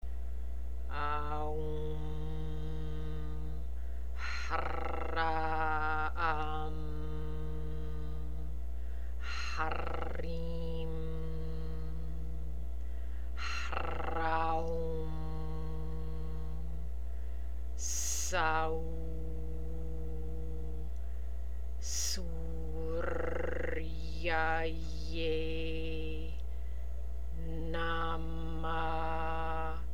МАНТРА ЗА СЛЪНЦЕТО:
Произношение:
AАА-УУУ-MMM ٠ Х-РР-AА- AА-MM ٠ Х-РР-ИИ-MM ٠ Х-РР-AА-УУ-MM ٠ ССА-УУУ ٠ СУУУ-РР-ИЯАА-ЙЕЙ ٠ НА-А-MA-А
Артикулирайте "Р" правилно.
1 - Sun Square Mantra.mp3